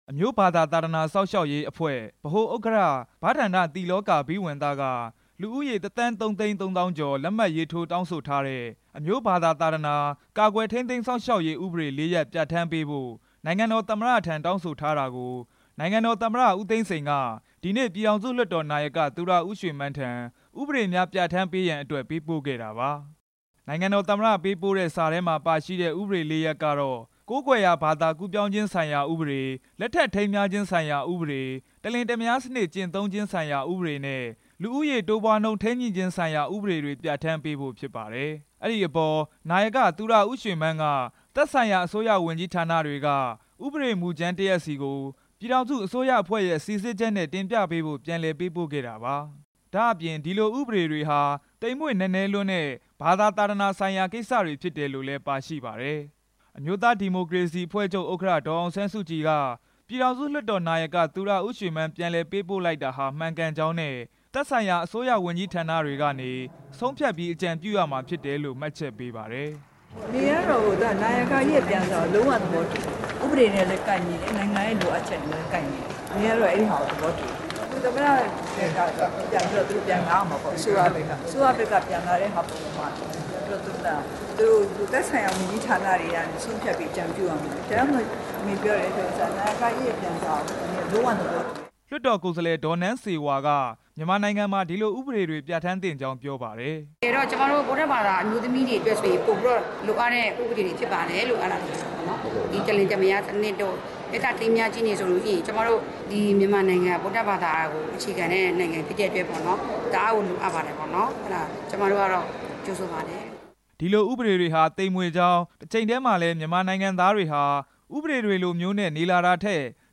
သတင်းပေးပို့ချက်